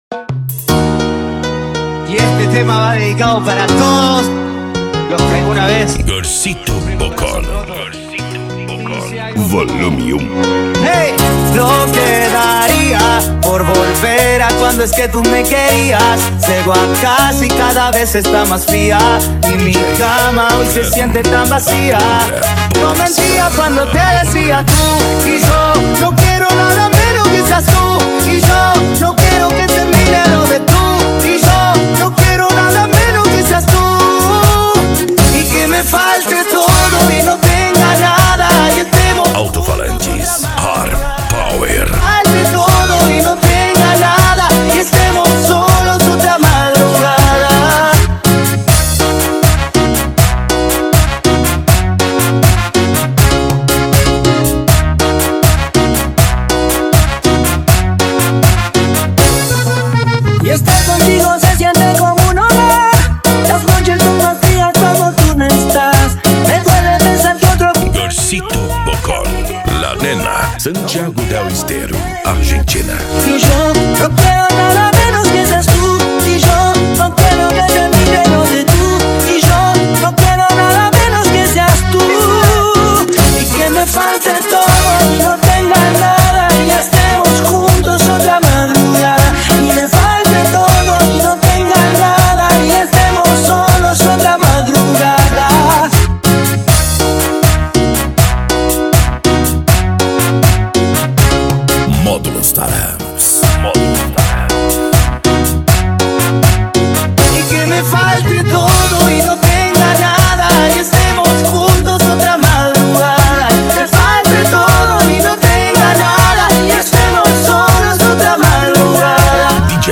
Remix
Bass